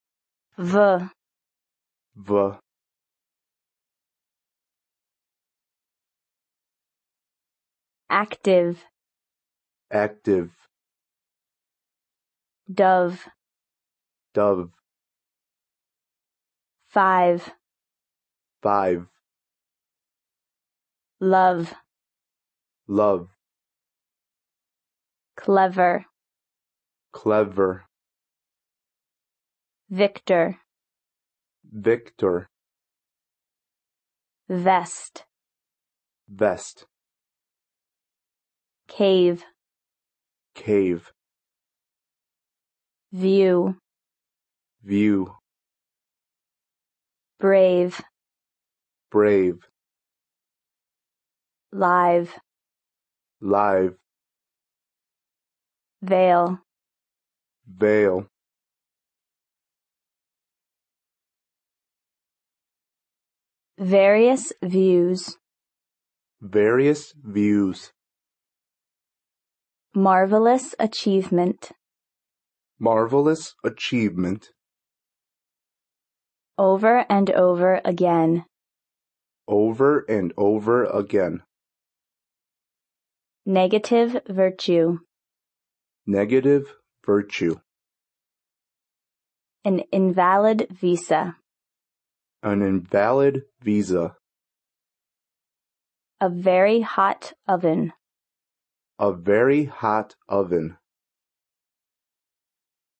英语国际音标：摩擦音[v] 听力文件下载—在线英语听力室